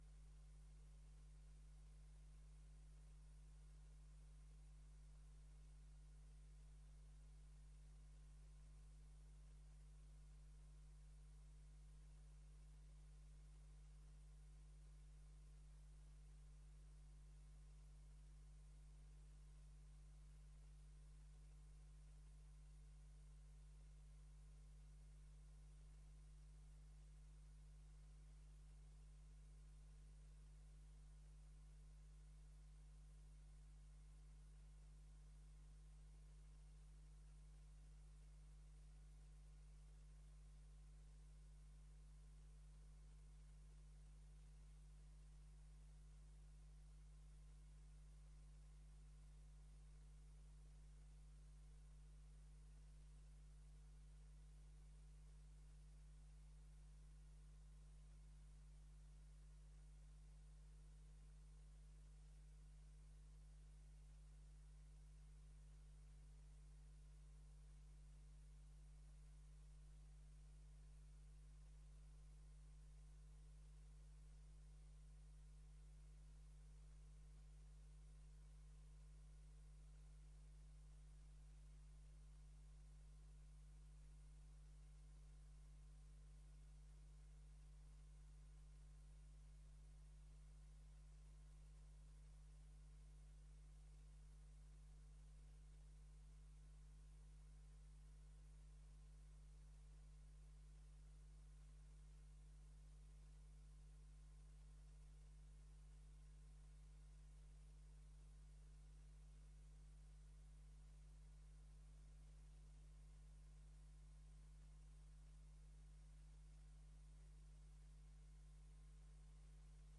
Gemeenteraad 10 juni 2024 21:00:00, Gemeente Dalfsen